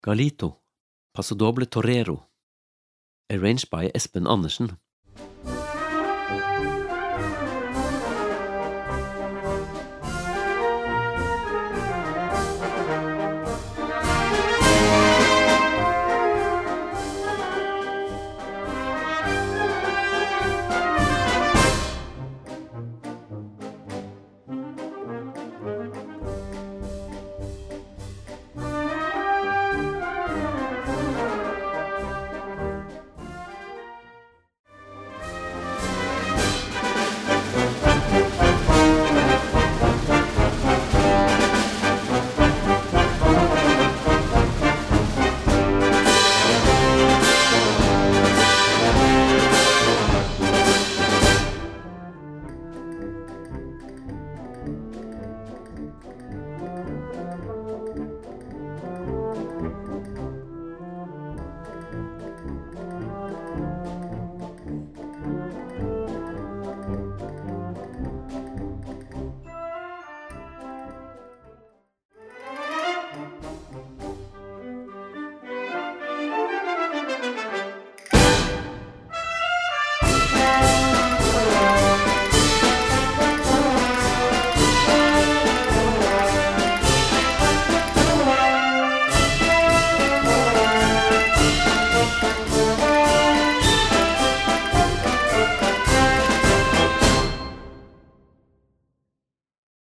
Gattung: Paso Doble
Besetzung: Blasorchester